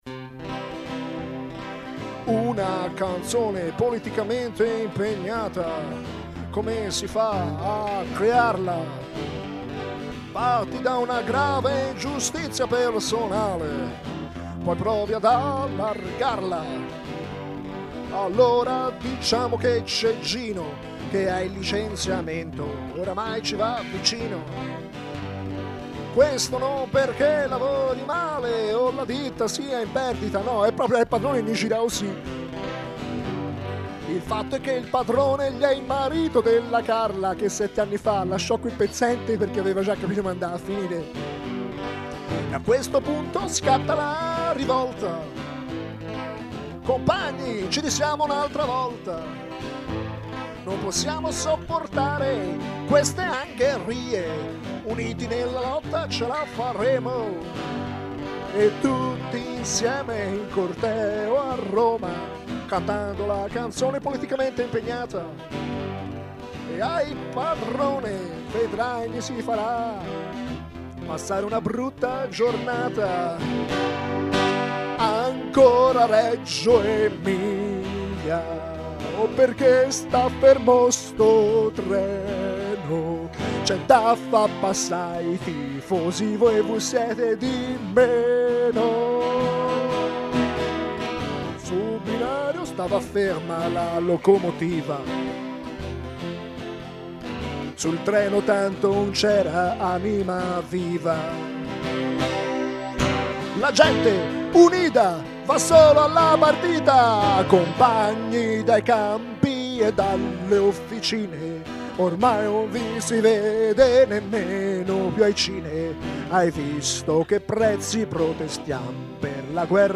Quando fai solo chitarra voce sei il mio eroe personale.